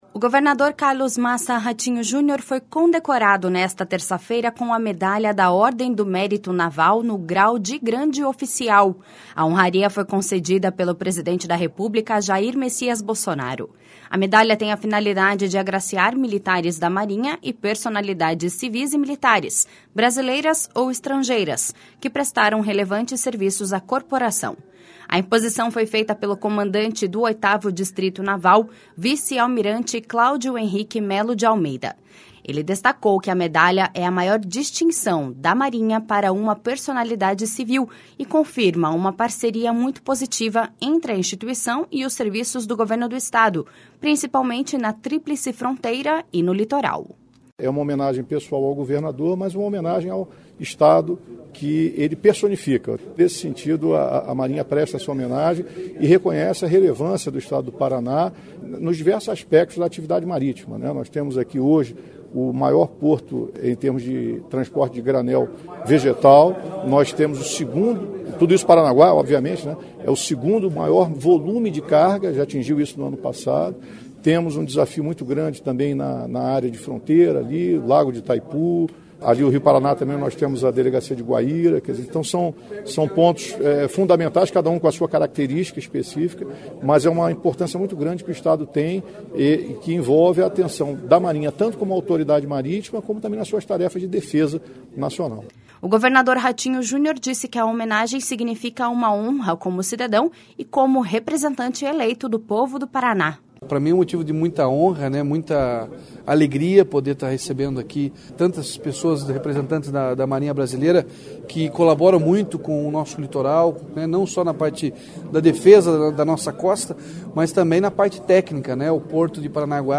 O governador Ratinho Junior disse que a homenagem significa uma honra como cidadão e como representante eleito do povo do Paraná.// SONORA RATINHO JUNIOR.//